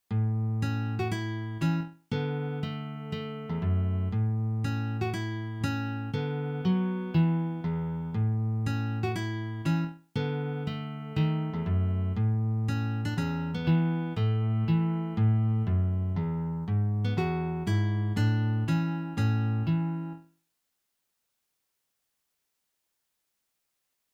für Gitarre solo
Sololiteratur
Gitarre (1)